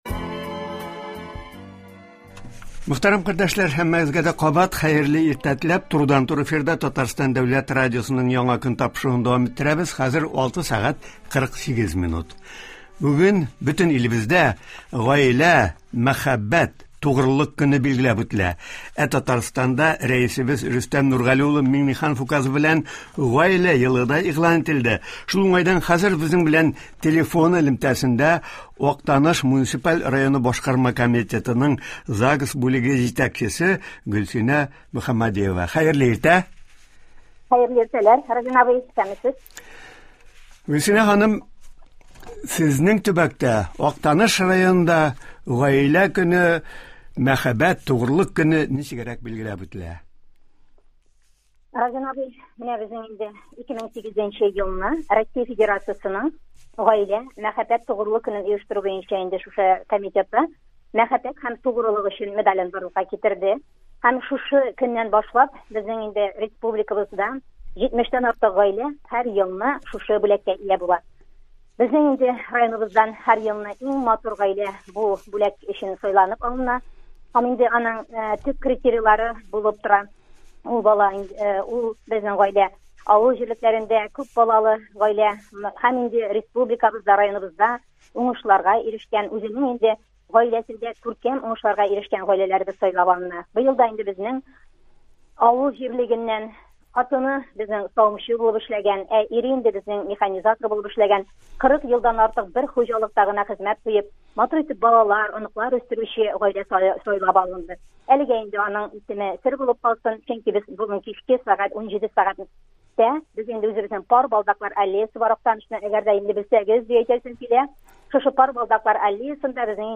Интервьюны